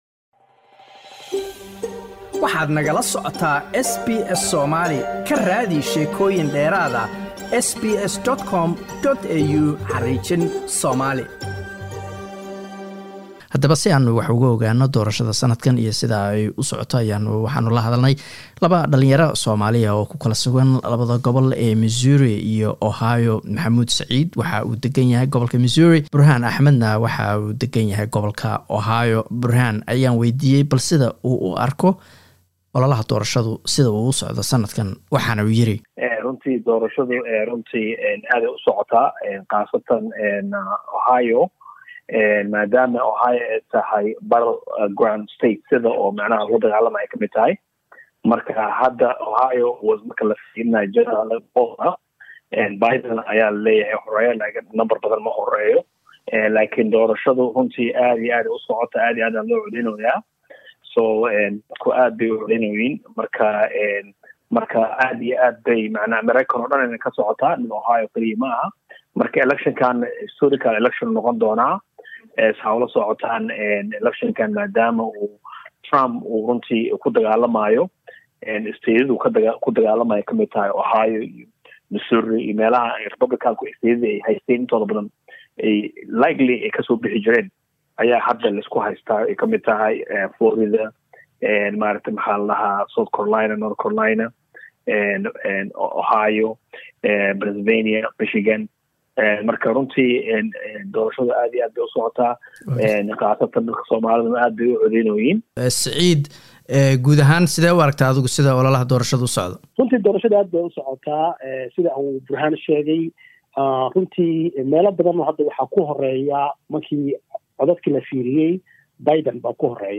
Waxaan waraysi la yeelanay labo aqoon yahan oo ku sugan dalka Maraykanka.